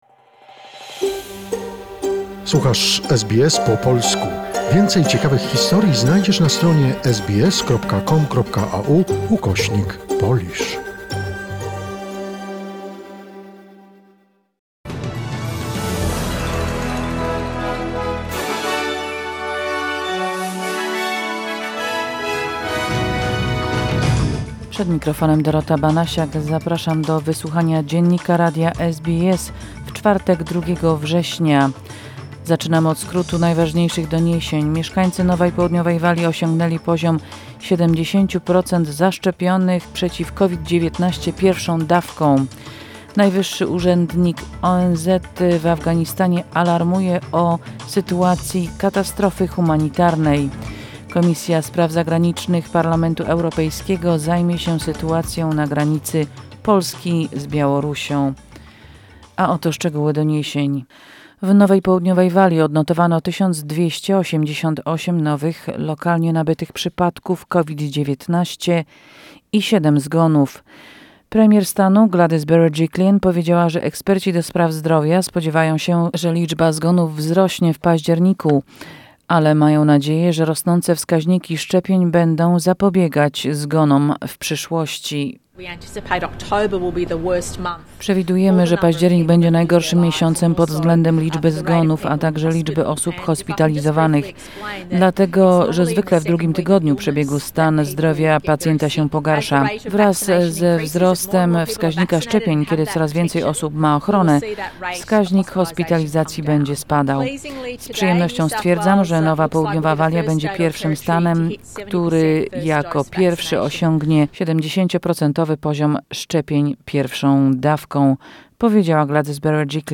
SBS News in Polish, 2 September 2021